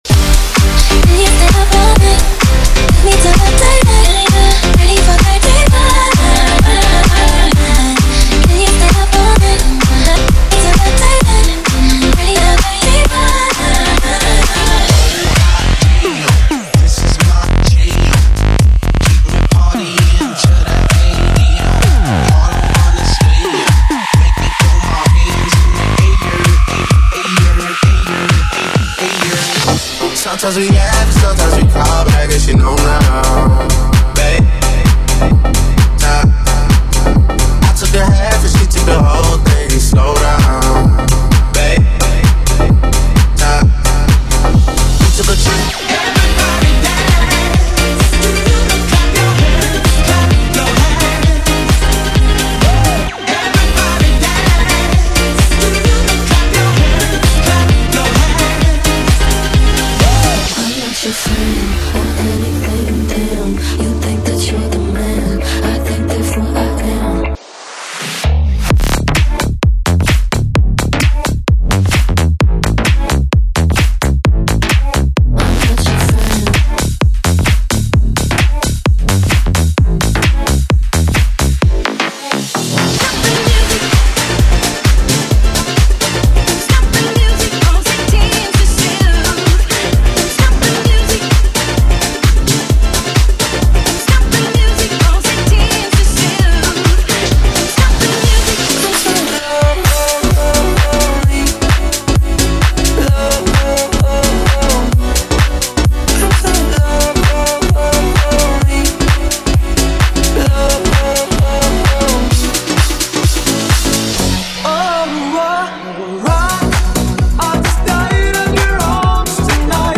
BPM: 130|140|150 (56:00)
Format: 32COUNT
Smooth House, Club & Anthem Sounds.